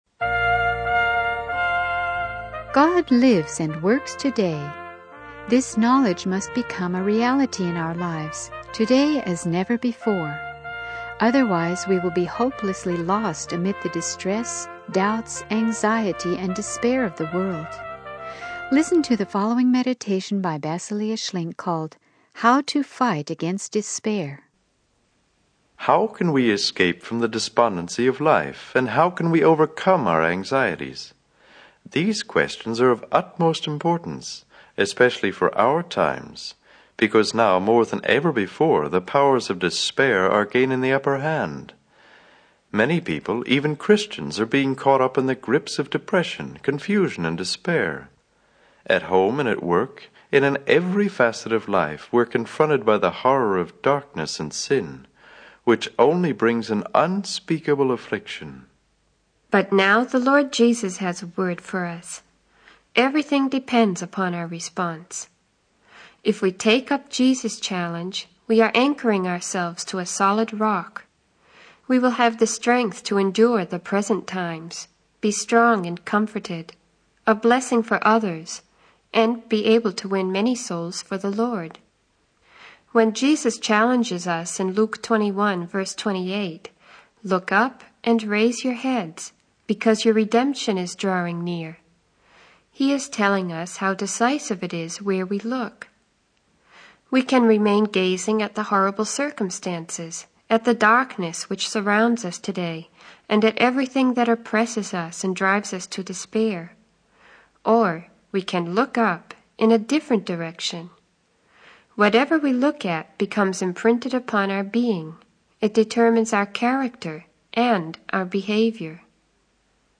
The sermon teaches that by focusing on Jesus, we can overcome despair and find true hope and strength in our lives.